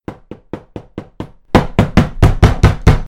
bang_a3MOI8a.mp3